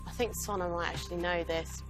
game show